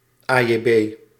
Ääntäminen
IPA: /tak/